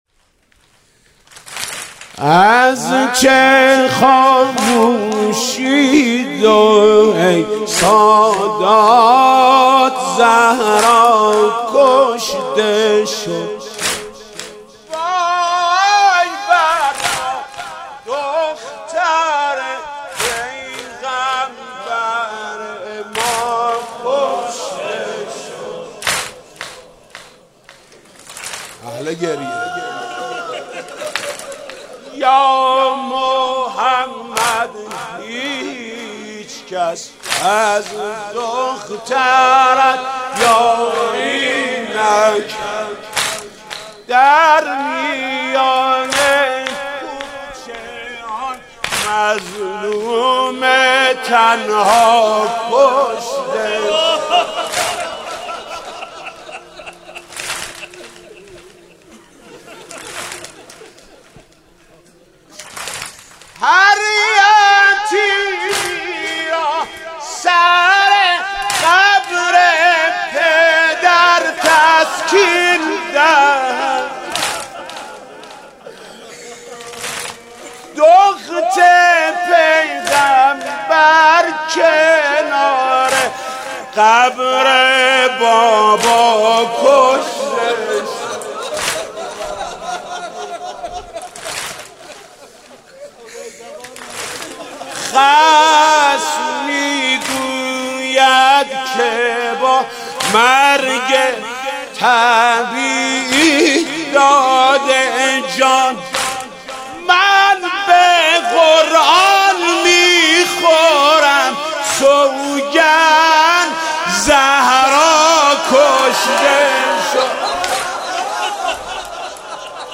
مداحی و نوحه
[شام شهادت] [واحد] [ُسال 95]